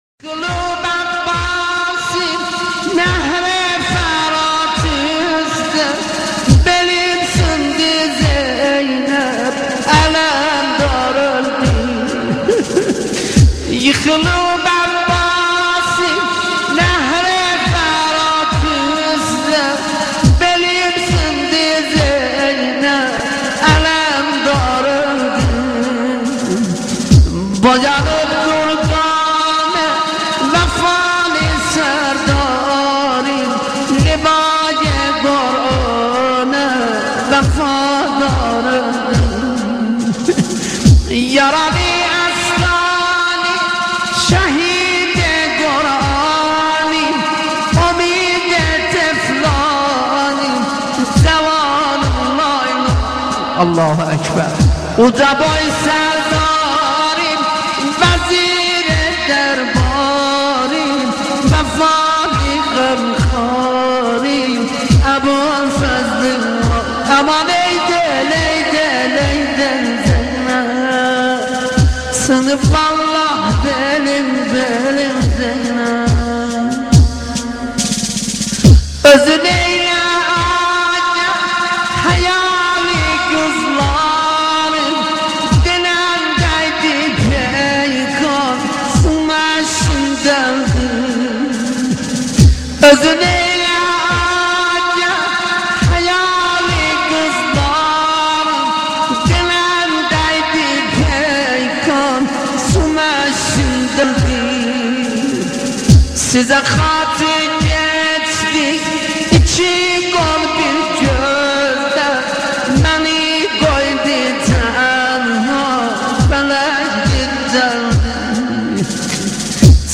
لطمية تركية بحق الامام الحسين و زينب (ع)
لطمية حزينه جداً